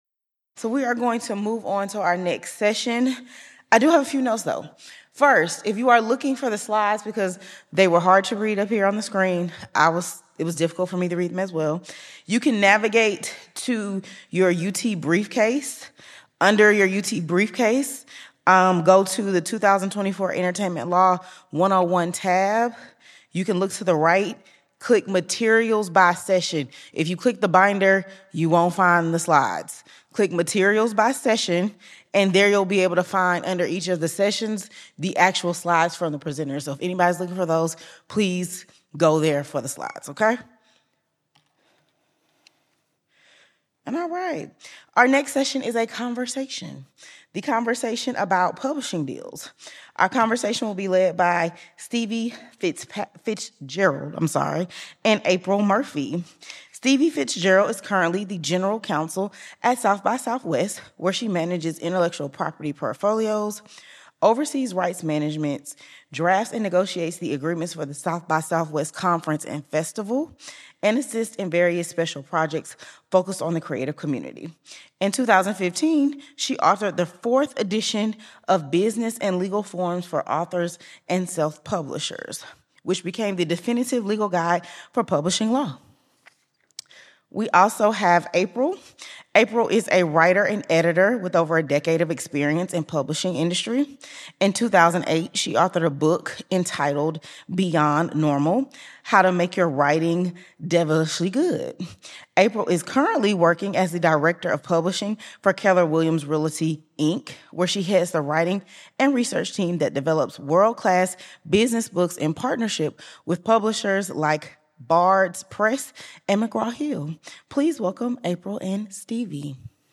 eCourse | Publishing Deals: A Conversation | UT Law CLE
An author and a publisher discuss legal and practical concerns of publishing agreements and ancillary deals.